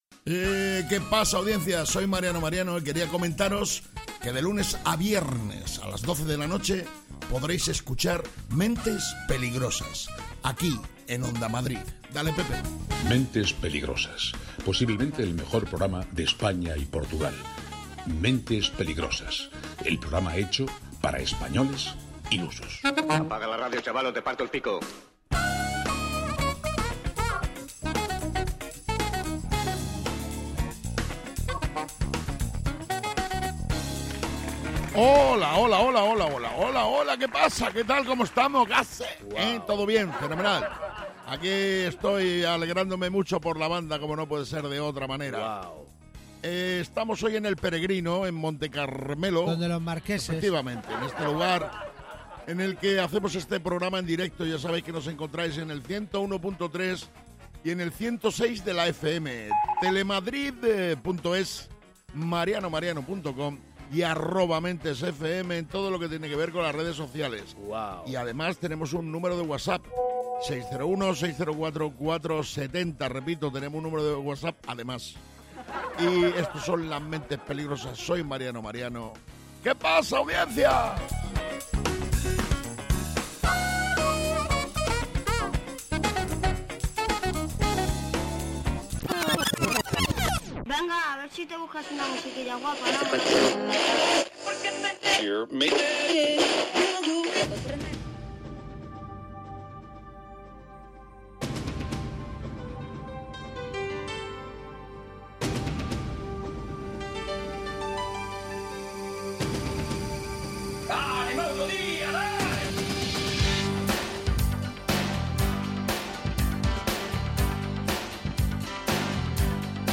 Mentes Peligrosas es un programa de radio, esto sí lo tenemos claro, lo que no está tan claro es qué pasará en cada una de sus entregas, no lo saben ni los que lo hacen, ni sus propios entornos. Mentes Peligrosas es humor, y quizás os preguntaréis, ¿y de qué tipo de humor es?